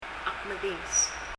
発音
medinges [mɛdíŋɛs]　　（おながが）いっぱいな、満足している